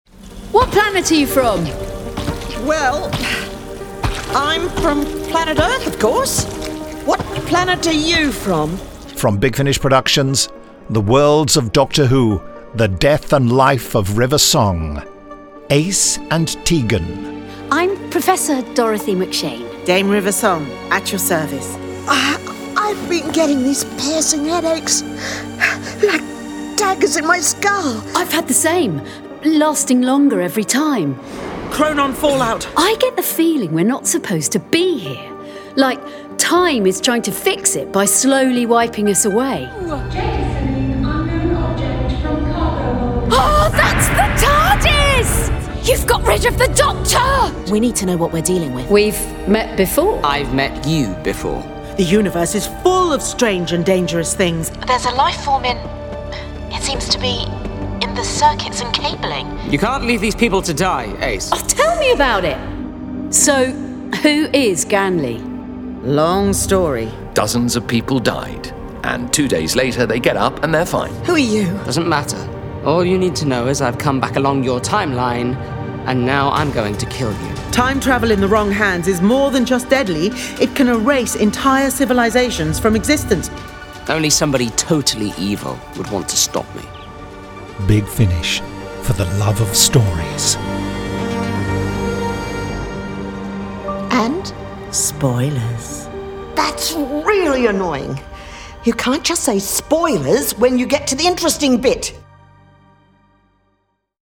Starring Alex Kingston Sophie Aldred